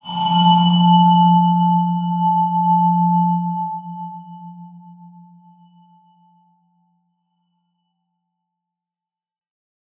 X_BasicBells-F1-ff.wav